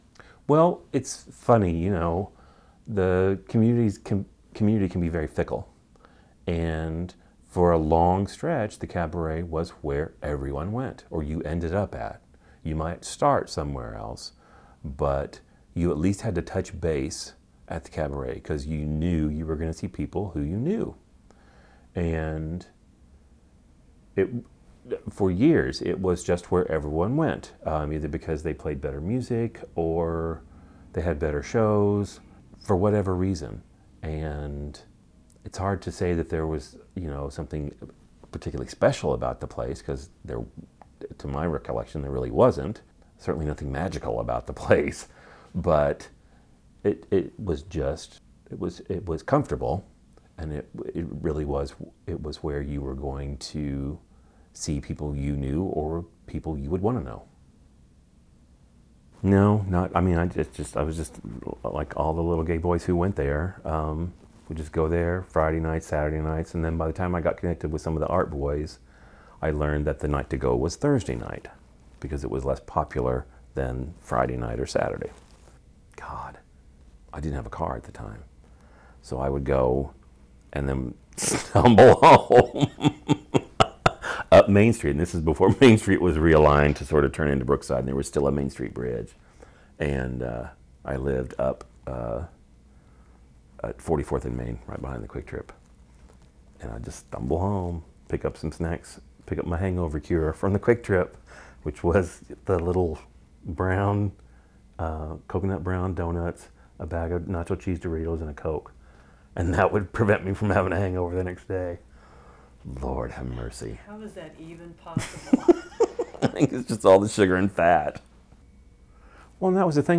I will be adding much more of this interview in the near future.